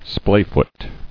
[splay·foot]